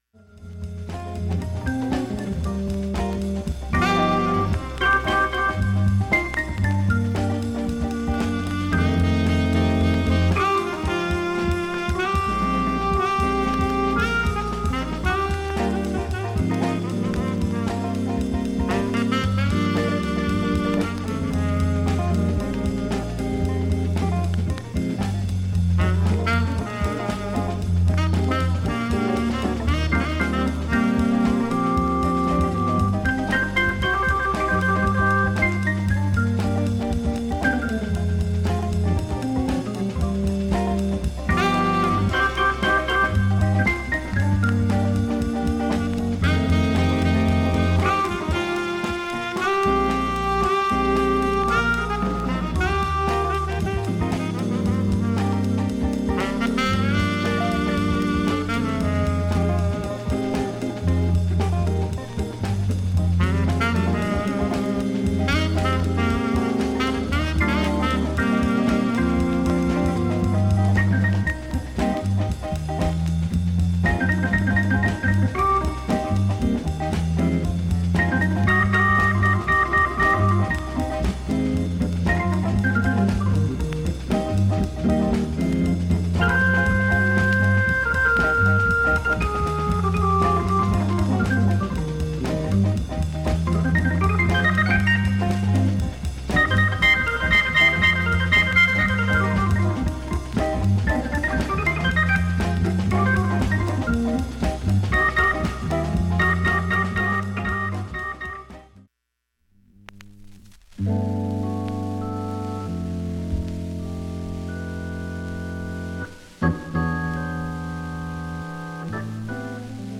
音質良好全曲試聴済み。
で55秒の間周回プツ出ますがかすかなレベル。
(4m05s〜)B-3序盤に８回プツ出ます。
PRESTIGE系ジャズファンク風味の
A-4A-5B-4は女性ヴォーカル入り。
マイナーレーベルオルガン奏者